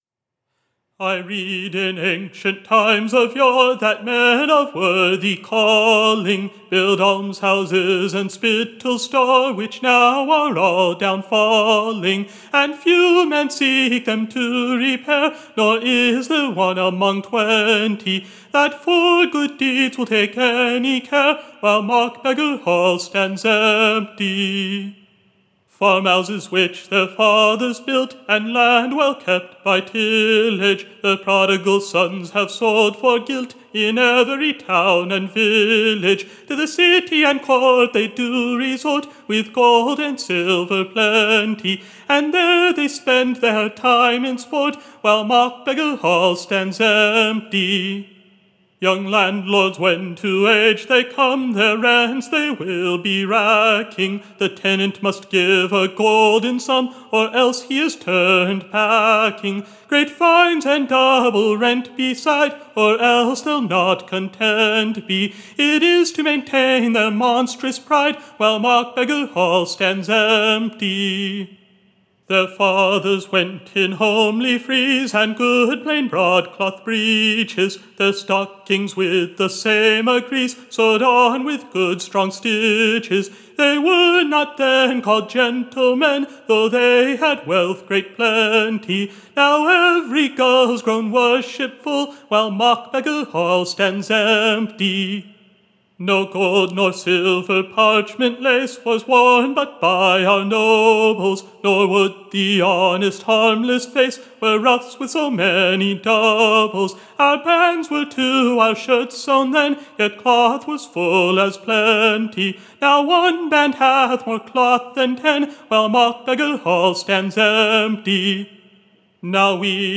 First “Mock-Beggar Hall” ballad